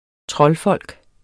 Udtale [ ˈtʁʌl- ]